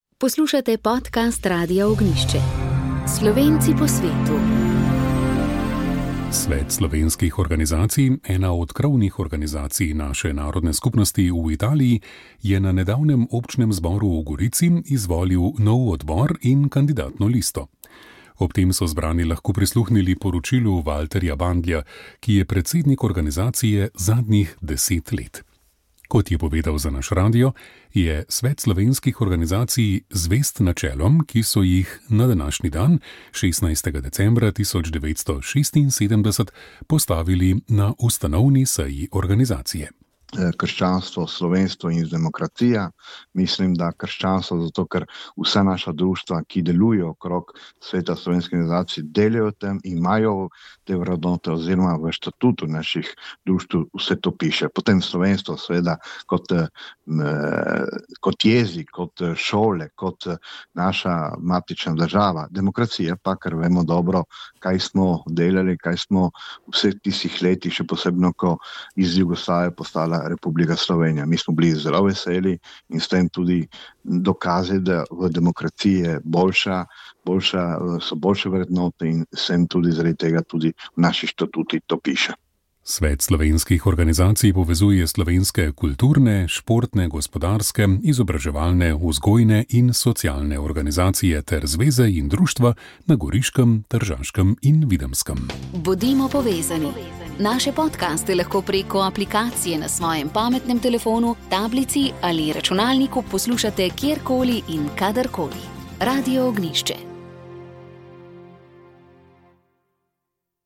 Rožni venec